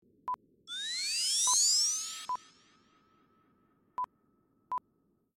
Zapper Whoosh Sound Design 1 (Sound FX)
Magic Wand, appear, whizz, zap sound design, Science Fiction Sound Effects, Magic Sounds
ZapperWhoosh_plip.mp3